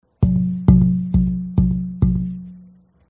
These are audio clips from the 2011 convention workshop.
The mate, sanded down. This demonstrates the variability of ping tone with thickness.